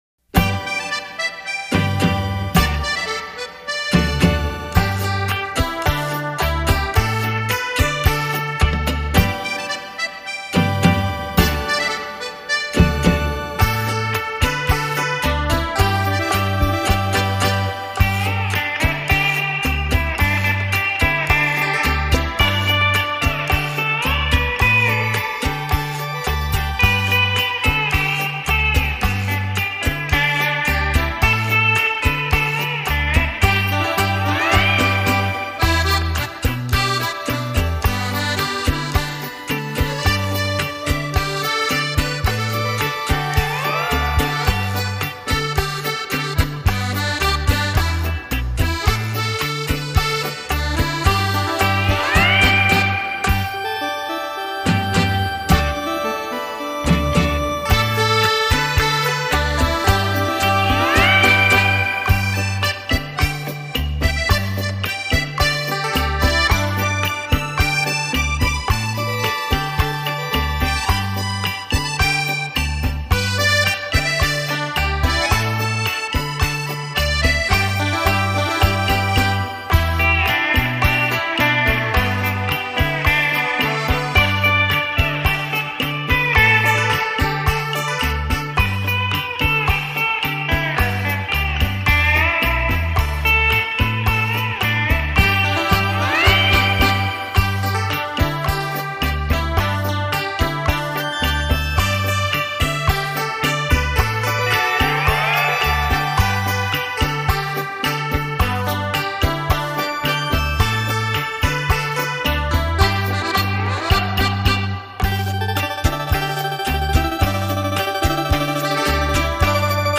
专辑类型：电子琴音乐